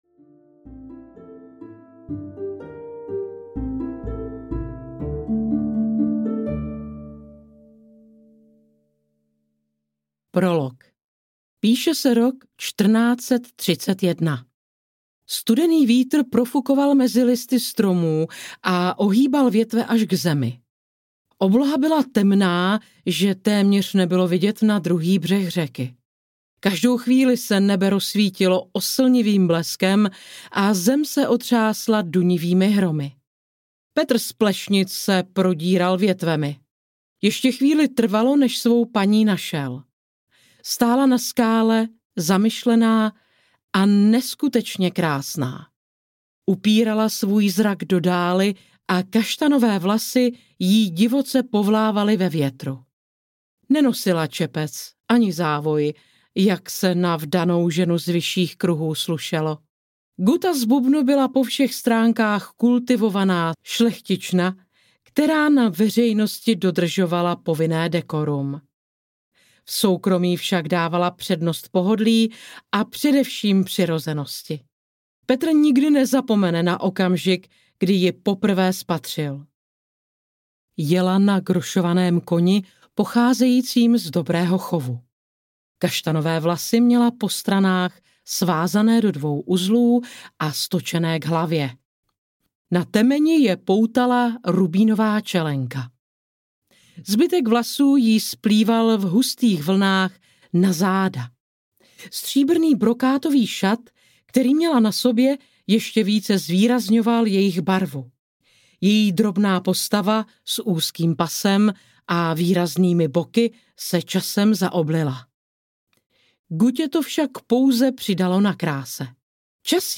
Gutta z Bubnu audiokniha
Ukázka z knihy